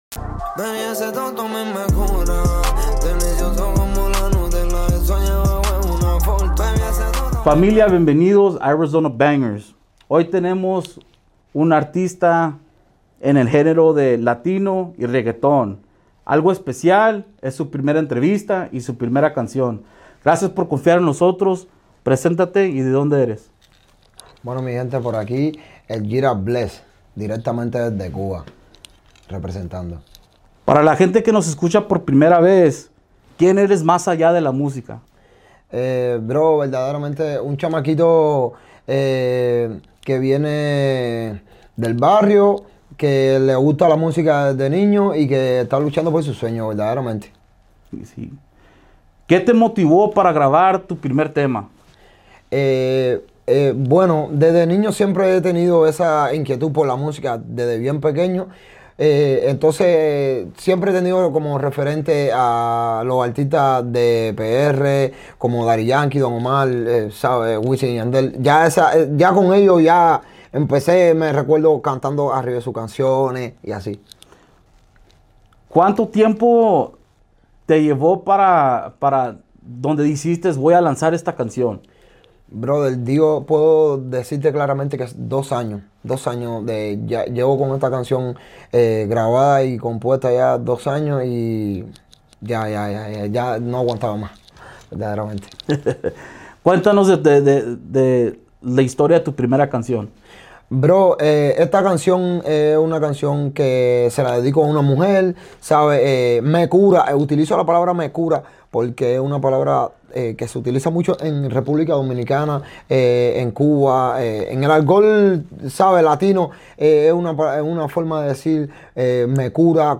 No te pierdas esta entrevista donde hablamos de música, metas, y el movimiento que está construyendo.